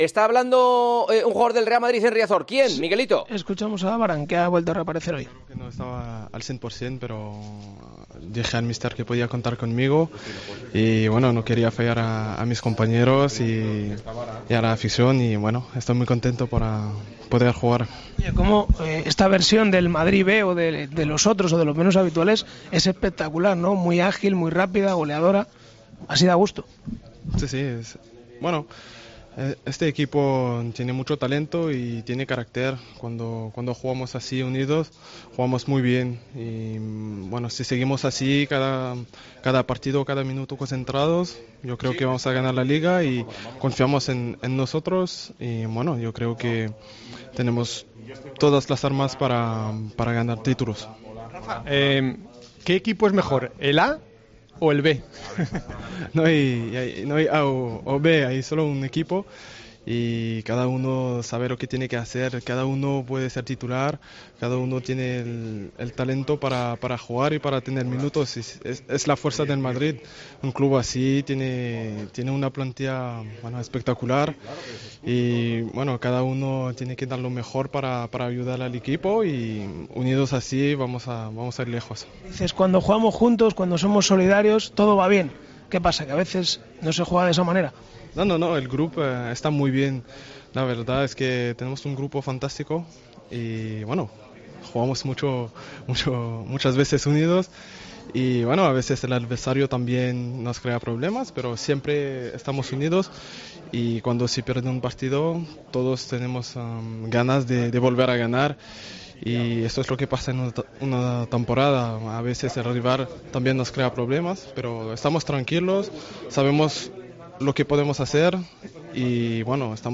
El central del Real Madrid valoró, en zona mixta, la goleada de los suplentes del conjunto blanco ante el Deportivo y el tramo final de la temporada.